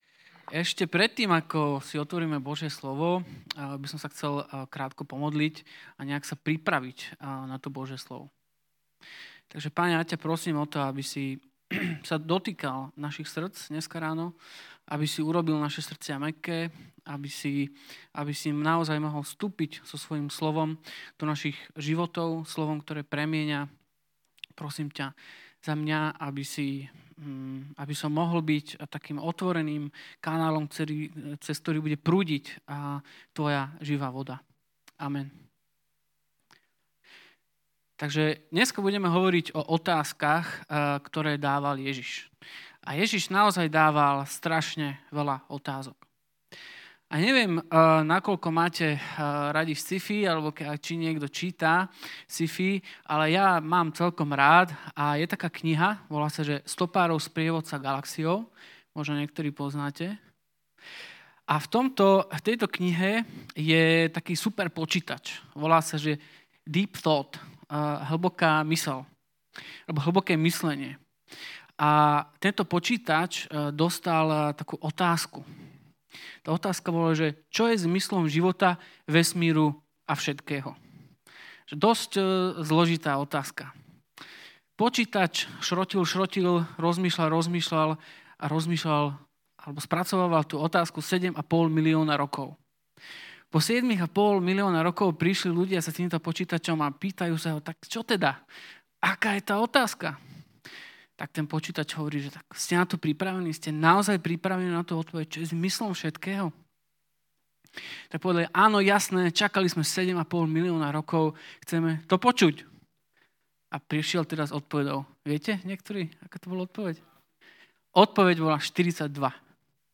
V sérii kázní s názvom "Keď sa Ježiš pýta" sa počas leta 2019 zameriame na otázky, ktoré sa pýtal Ježiš počas svojho života.